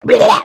Minecraft Version Minecraft Version snapshot Latest Release | Latest Snapshot snapshot / assets / minecraft / sounds / entity / shulker / ambient4.ogg Compare With Compare With Latest Release | Latest Snapshot
ambient4.ogg